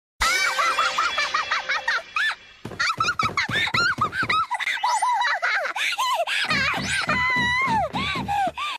Anime Girl Laugh Attack